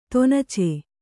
♪ tonace